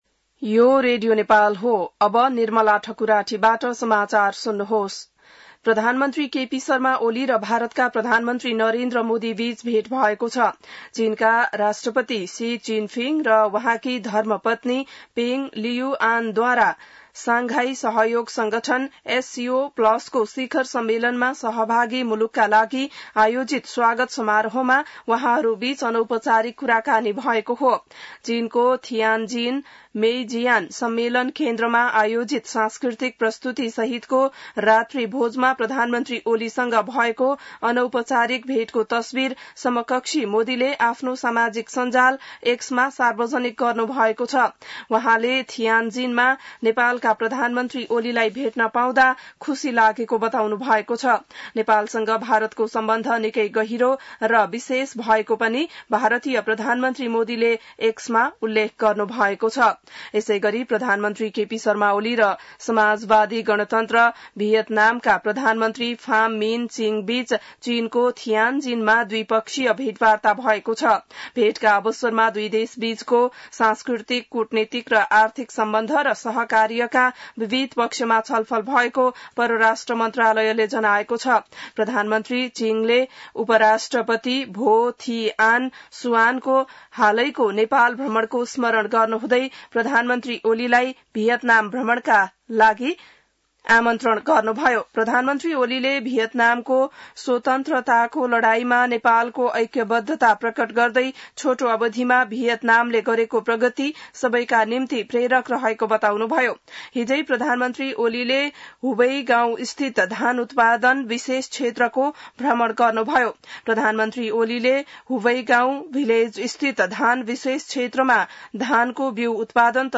बिहान ६ बजेको नेपाली समाचार : १६ भदौ , २०८२